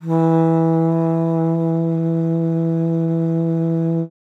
42e-sax02-e3.wav